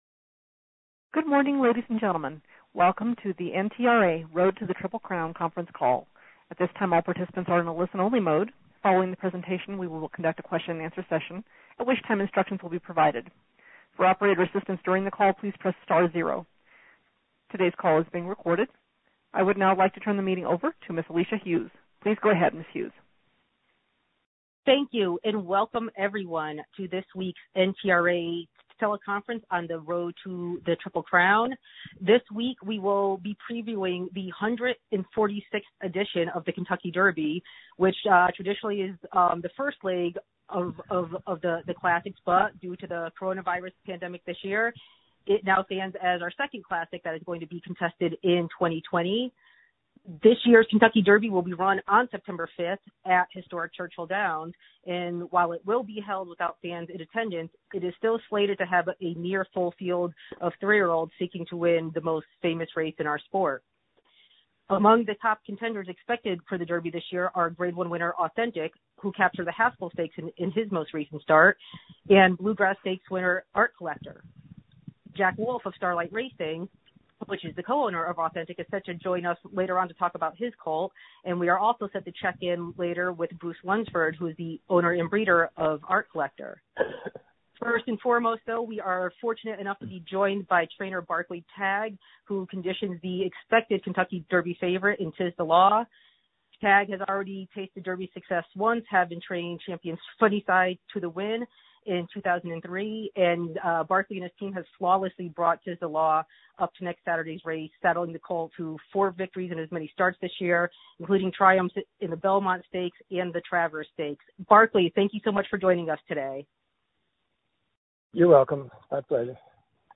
At this time, all participants are in a listen only mode.
Today’s call is being recorded.